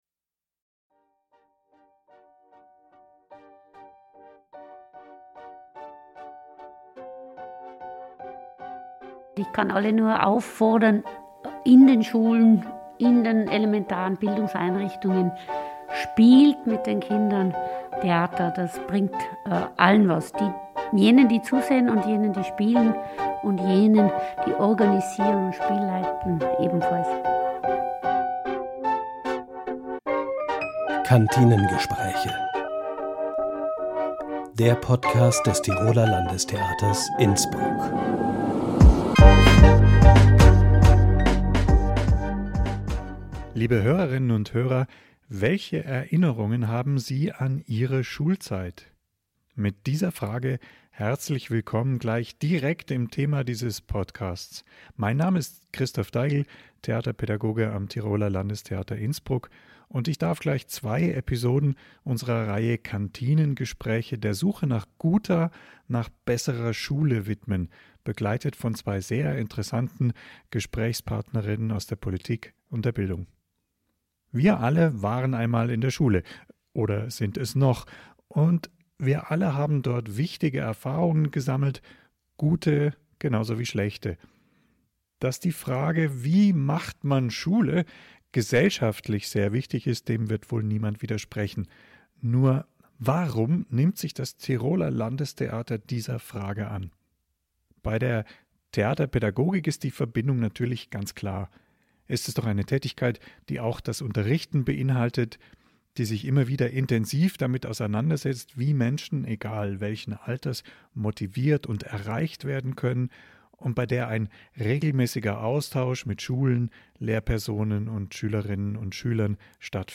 Headliner Embed Embed code See more options Share Facebook X Subscribe Was macht eine Schule zu einer guten Schule? Was können Kunst und Kultur, Theater und kreativ-spielerische Zugänge hier beitragen? Diesen und anderen Fragen rund um kulturelle Bildung geht Landesrätin Dr. Beate Palfrader im Gespräch